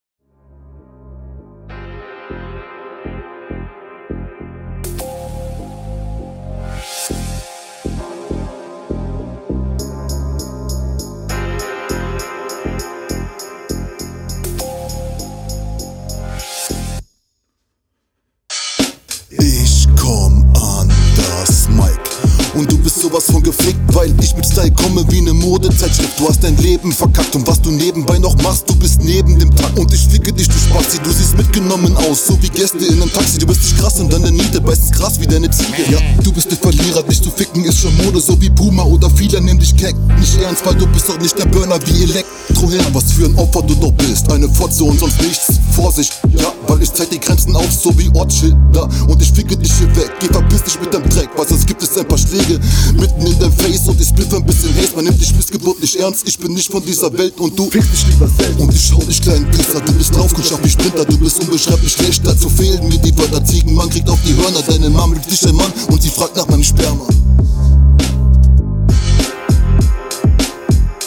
Cooler Beat schonmal!